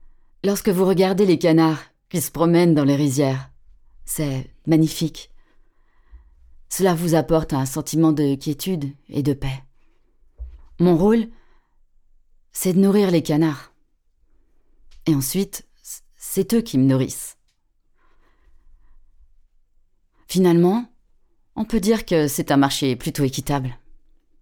VOICE OVER DOCU